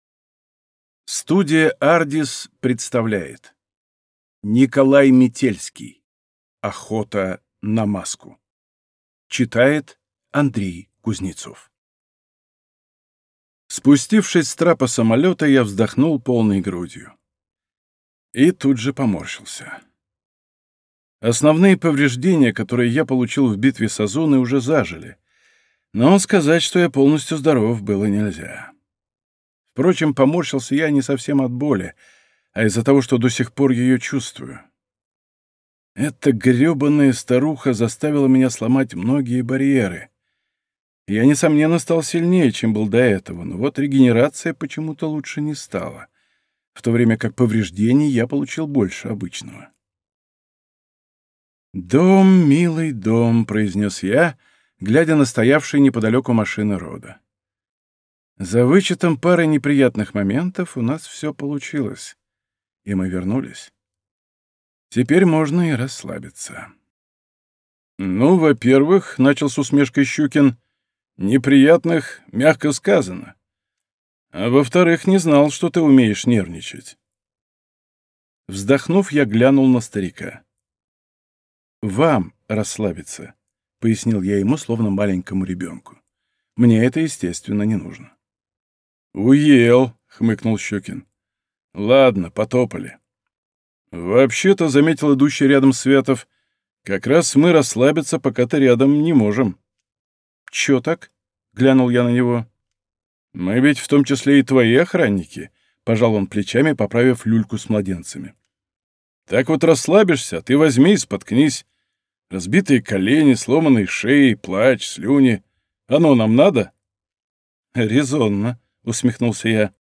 Аудиокнига Охота на маску. Часть первая | Библиотека аудиокниг